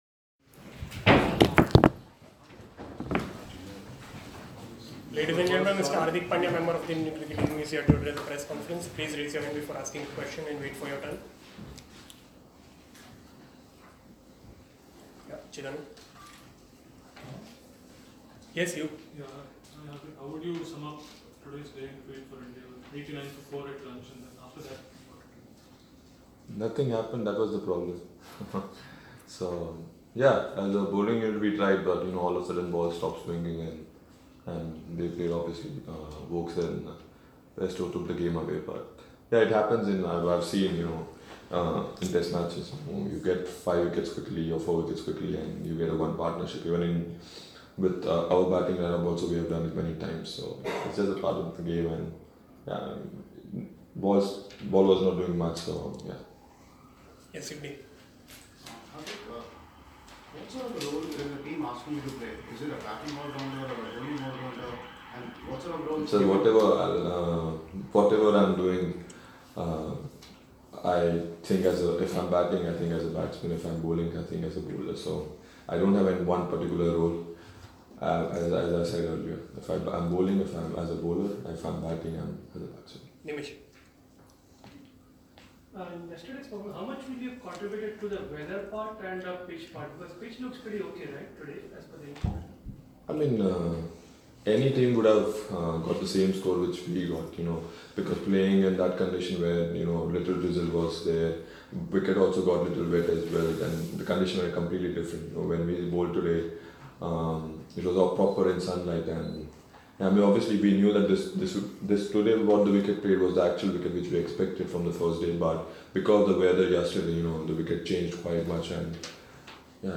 Hardik Pandya speak to the media after day 3 of the second Test at the Lord's, London